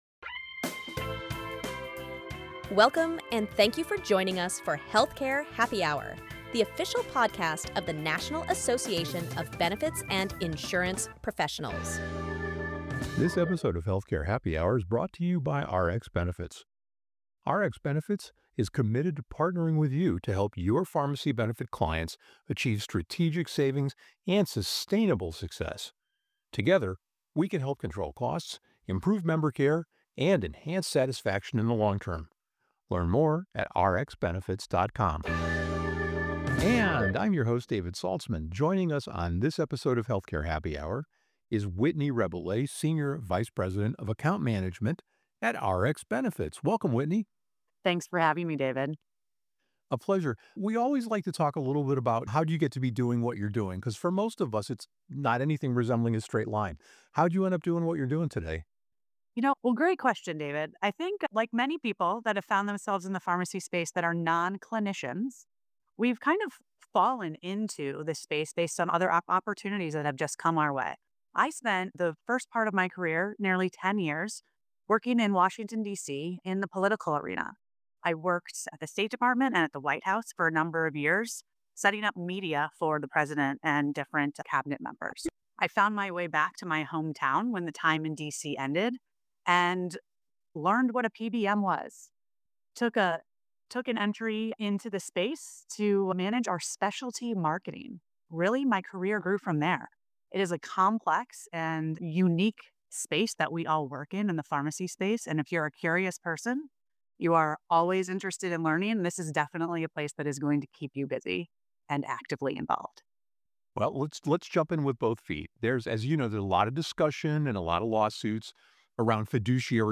The conversation also covers formulary design, pharmacy network options, and the ongoing legislative trends affecting the industry.